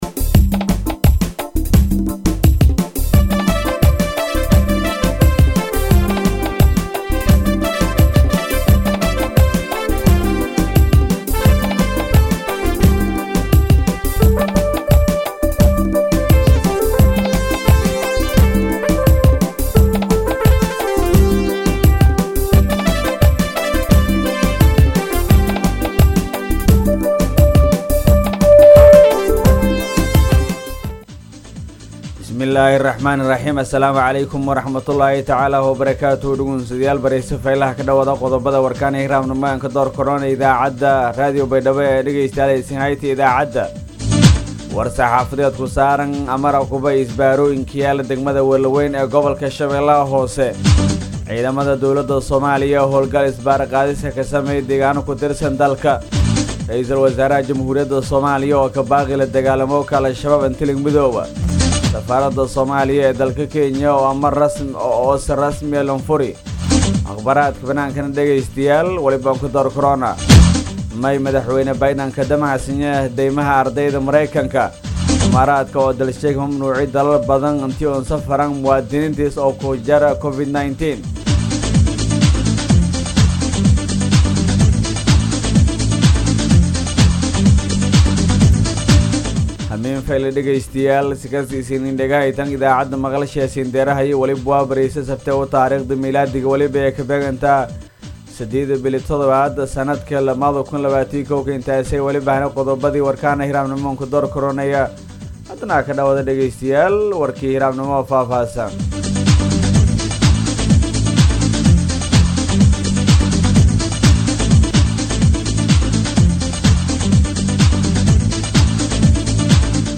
DHAGEYSO:- Warka Subaxnimo Radio Baidoa 3-7-2021
BAYDHABO–BMC:–Dhageystayaasha Radio Baidoa ee ku xiran Website-ka Idaacada Waxaan halkaan ugu soo gudbineynaa Warka ka baxay Radio Baidoa.